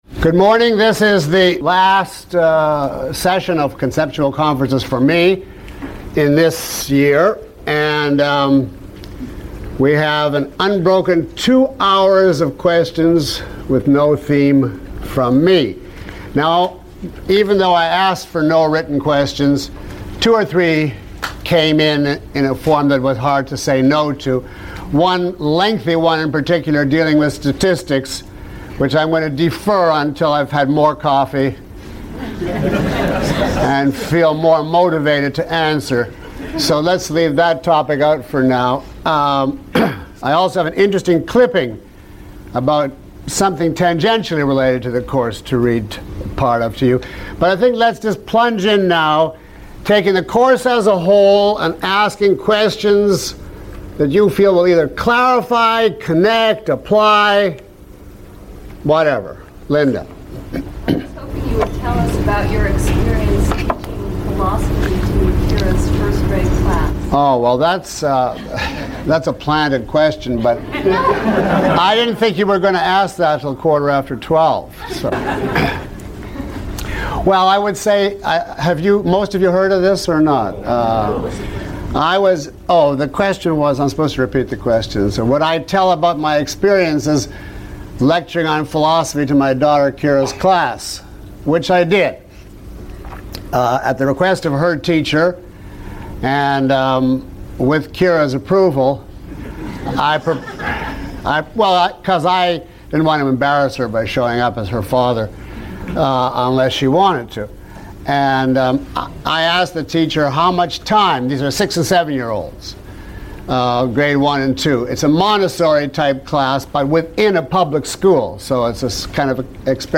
A question and answer session for course attendees.